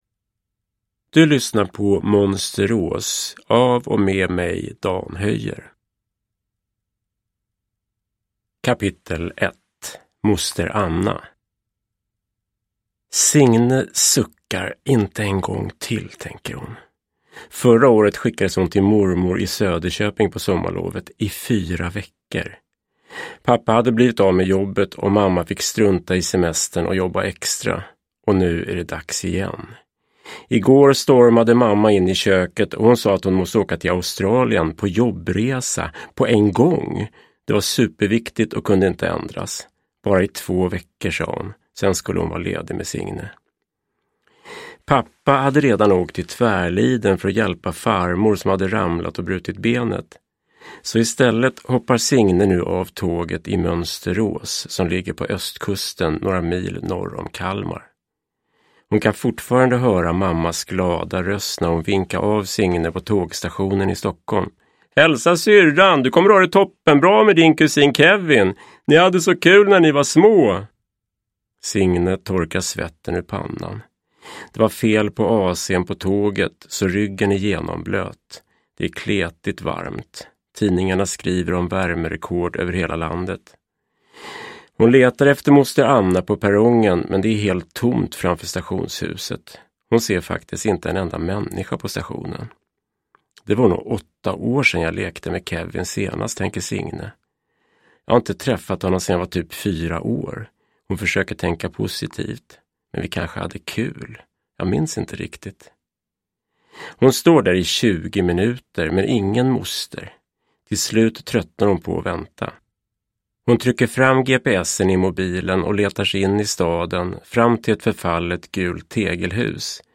Monsterås – Ljudbok